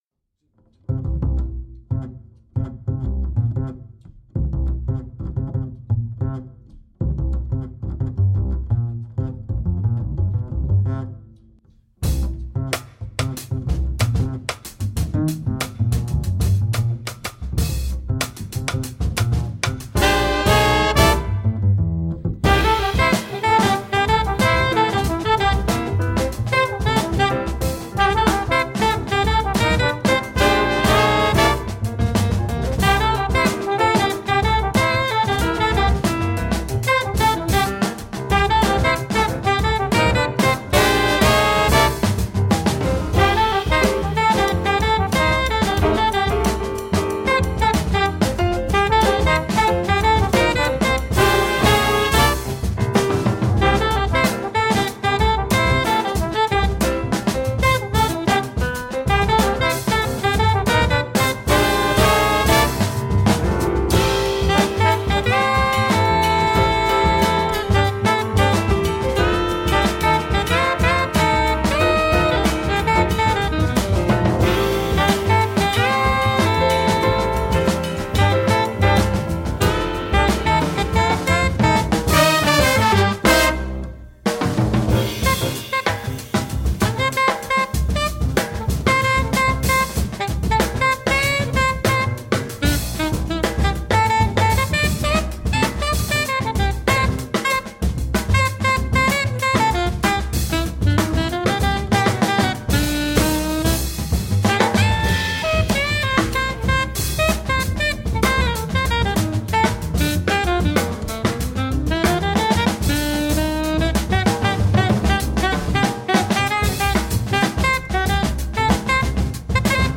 Modern original jazz from austria.
It is quite simply structured and has a relaxed mood.
Tagged as: Jazz, Rock